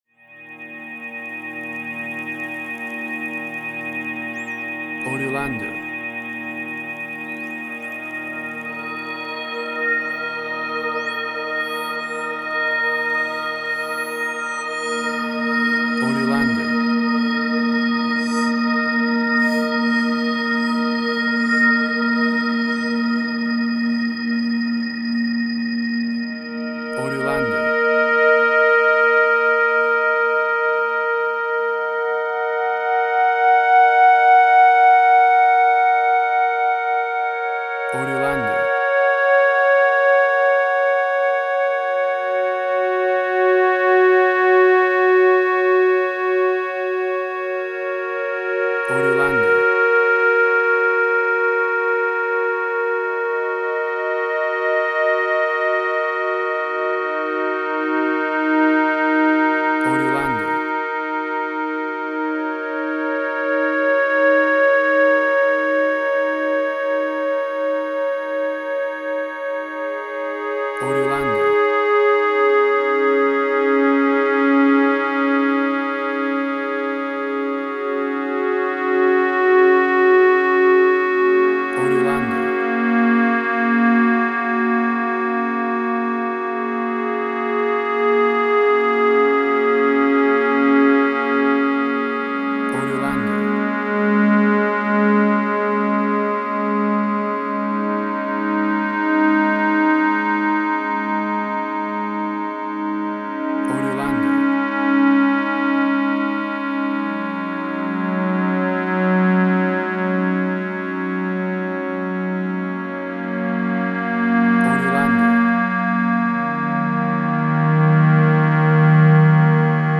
Ambient Strange&Weird